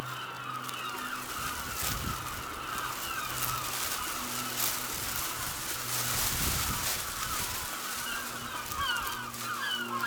Plastic cover
I was recording in a back garden when I noticed a sound I really liked, above all the screaming coming from the seagulls. There was a piece of furniture to the side, protected with a large piece of plastic, which Margate’s plentiful wind was happily playing.